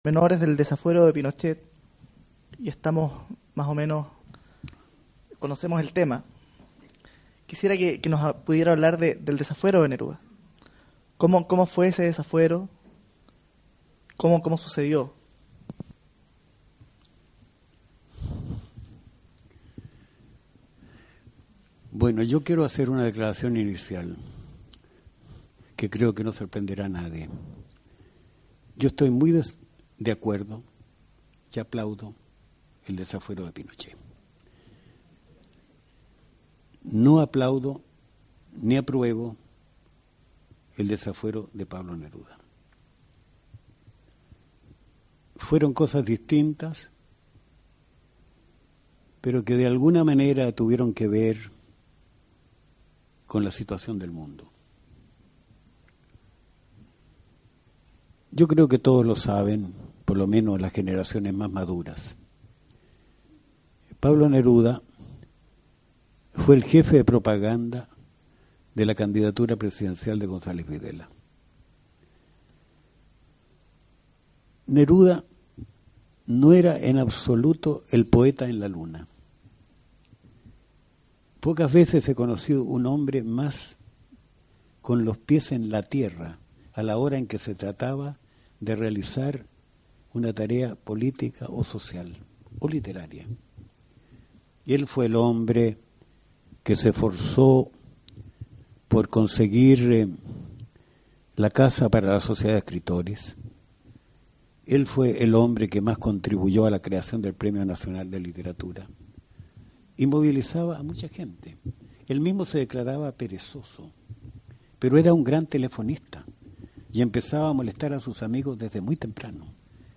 Volodia Teitelboim deslumbra con sus capacidades oratorias, con la ordenada y prolija presentación de sus ideas.
VOLODIA TEITELBOIM – Charla sobre Canto General de Neruda.